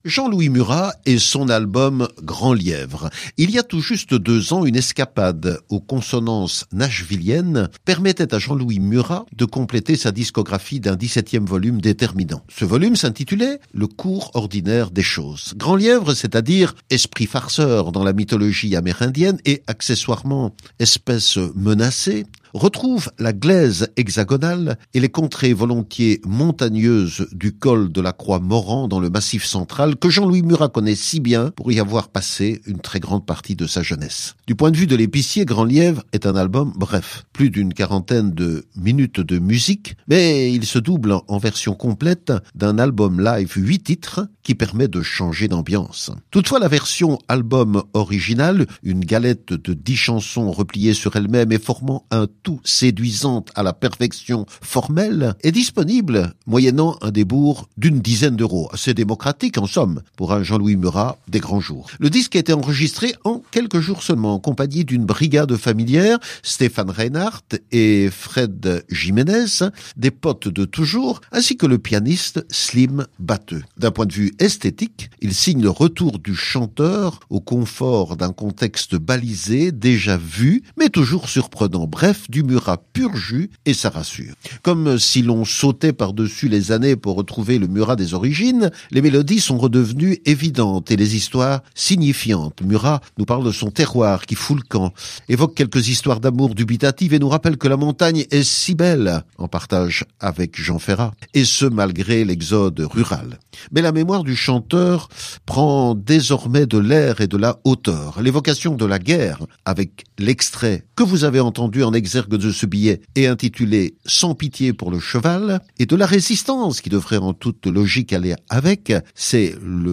Enregistré en quelques jours dans le sud de la France